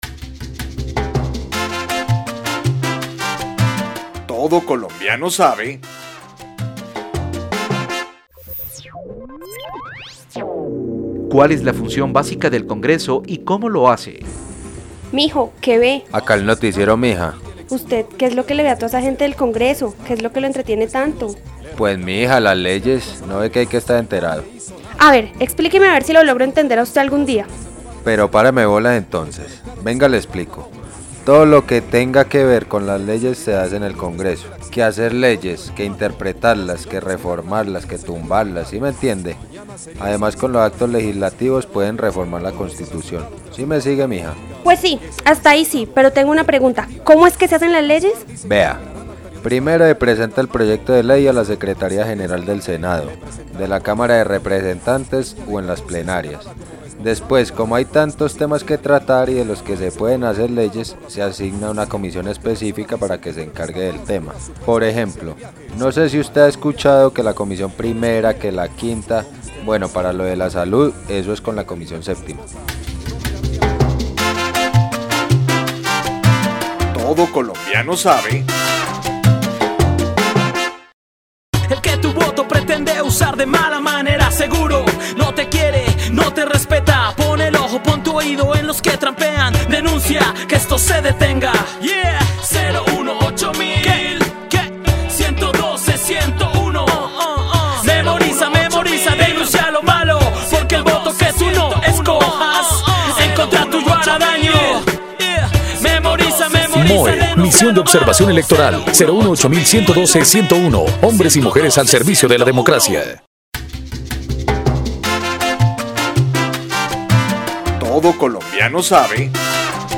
Programas de radio , Participación ciudadana en Colombia , Elecciones y democracia en Colombia , Control político y rendición de cuentas , Irregularidades y delitos electorales , Colombia -- Grabaciones sonoras